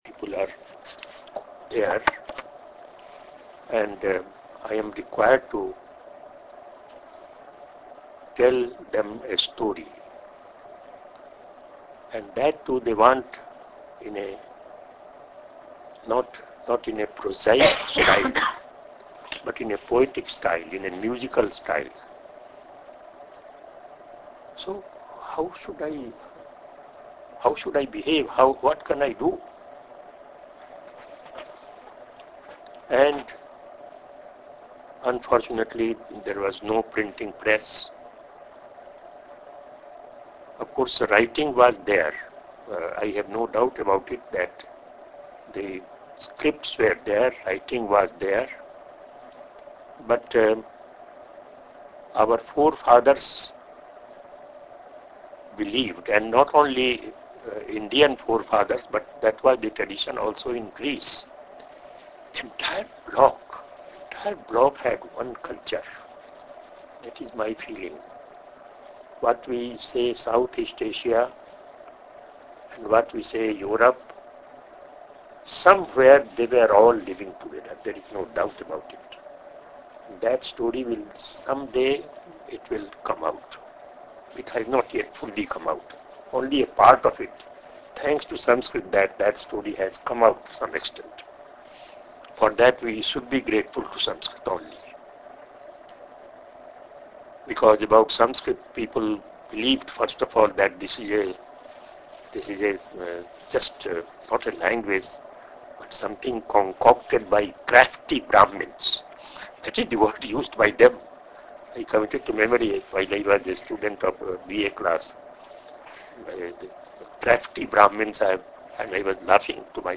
Mixing Sanskrit with English presents a challenge for computer transcription.
Here is an unedited transcript of an audio file from the University of Pennsylvania lectures as an example.